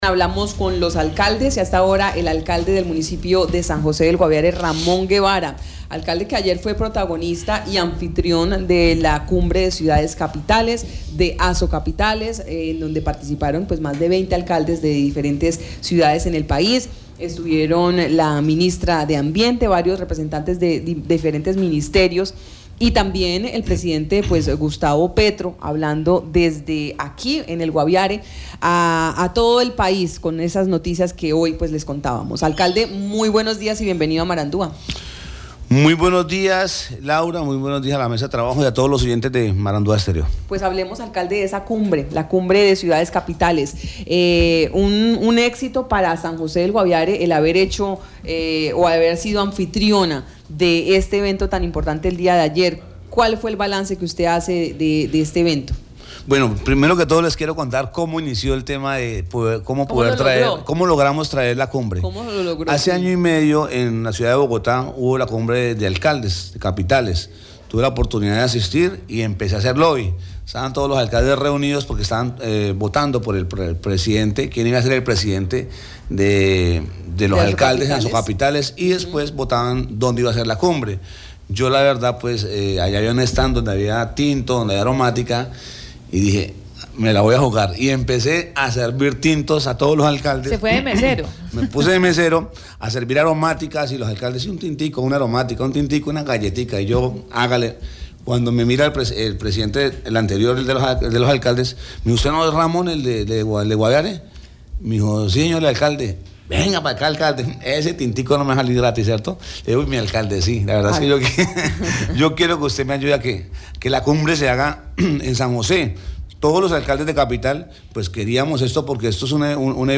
Ramón Guevara, alcalde de San José del Guaviare, en Marandua Noticias narró cómo se logró que San José del Guaviare fuera la capital sede de XXI Cumbre de Ciudades Capitales.